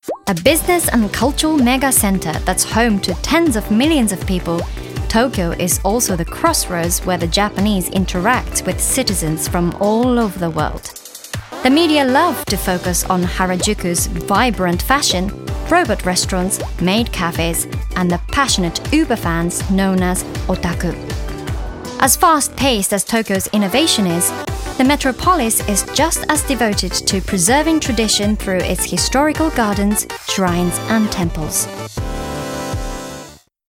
Japanese, Female, Teens-20s